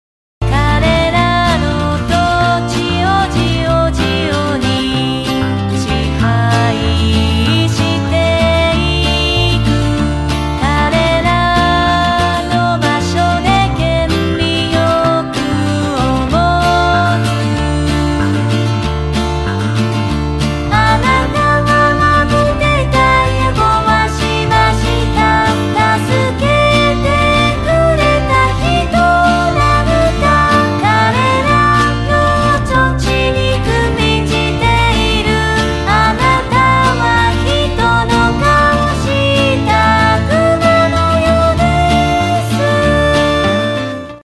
the bass is so deep